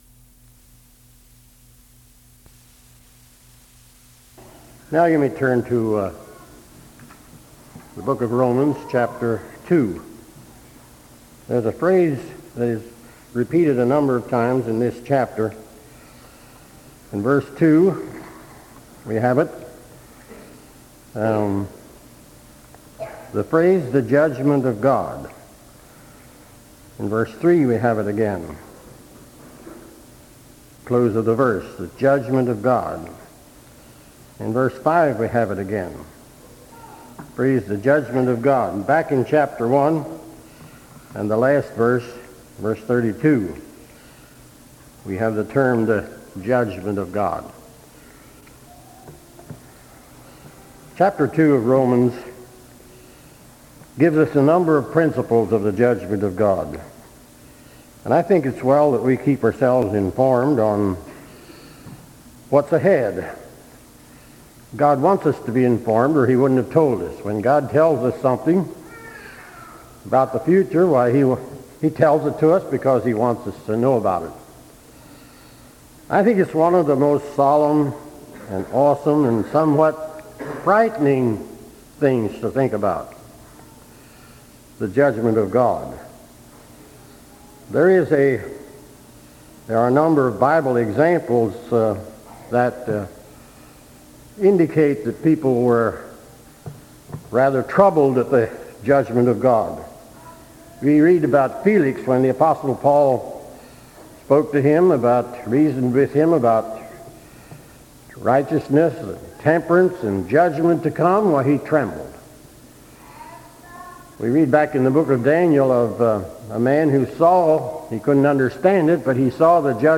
1998 Sermon ID